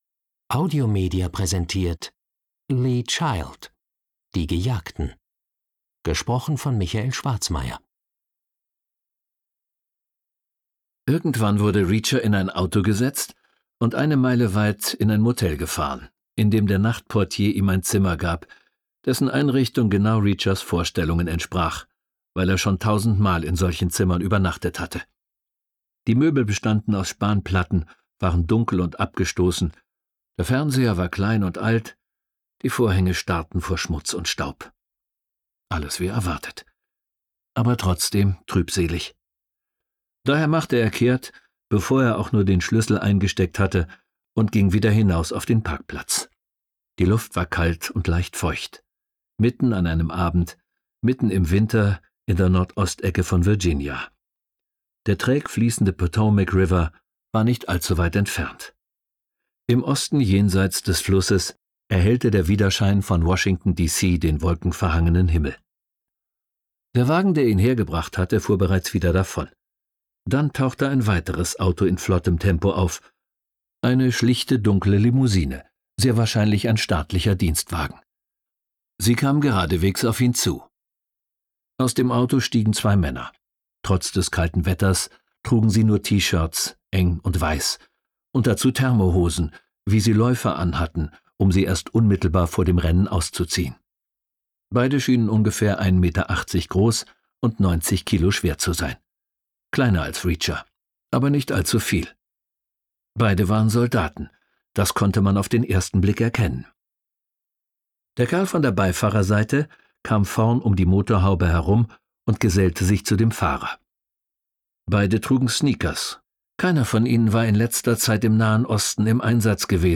Hörbuch; Krimis/Thriller-Lesung